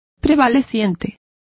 Also find out how prevalecientes is pronounced correctly.